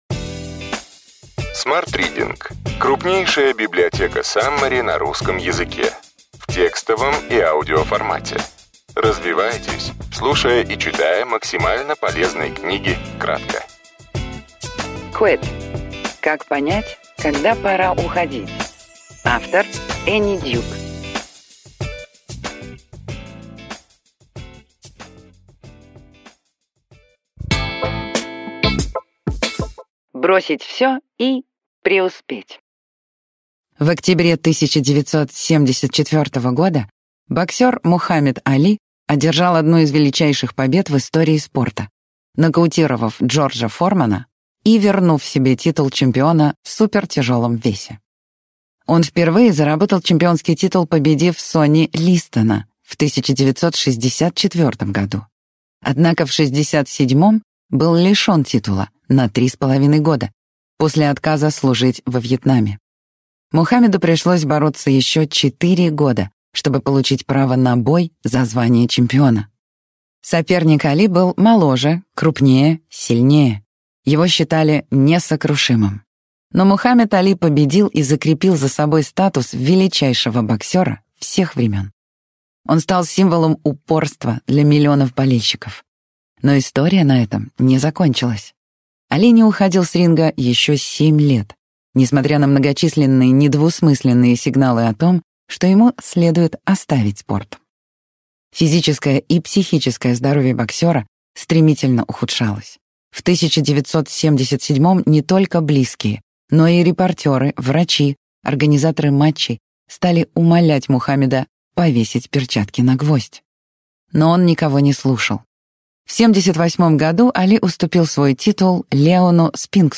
Аудиокнига Quit. Как понять, когда пора уходить. Энни Дьюк. Саммари | Библиотека аудиокниг